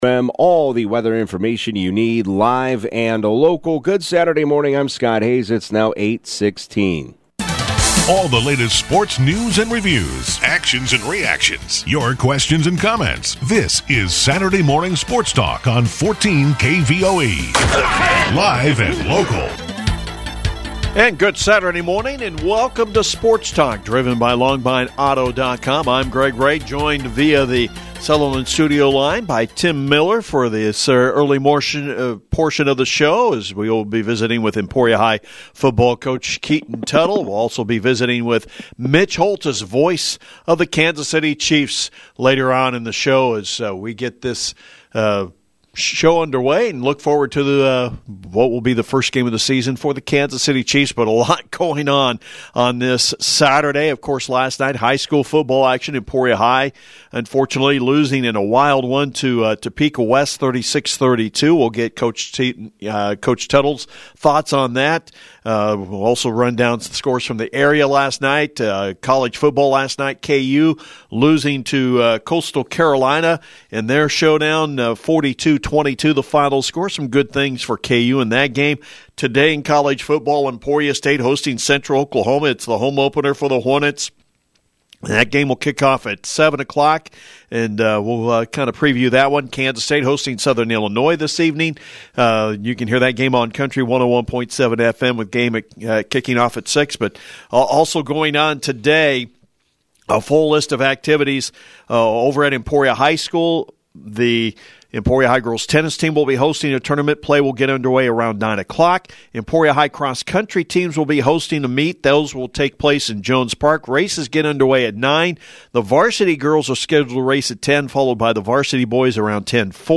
91121-saturday-sports-talk.mp3